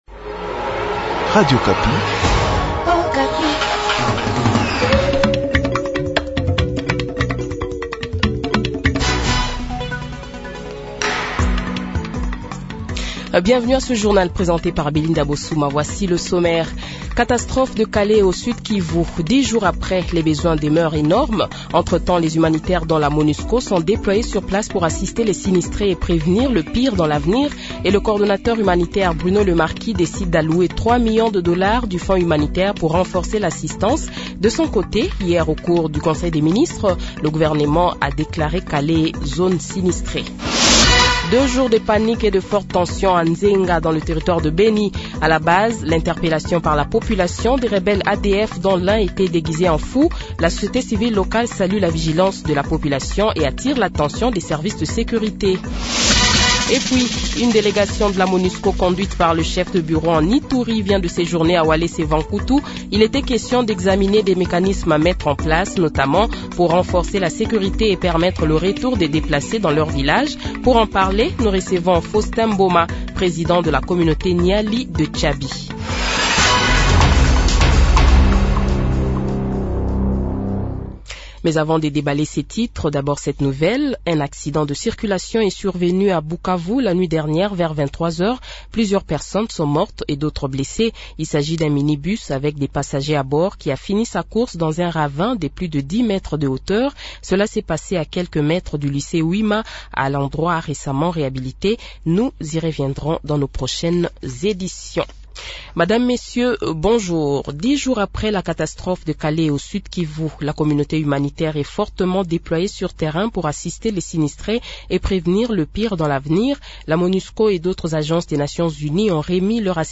Le Journal de 12h, 13 Mai 2023 :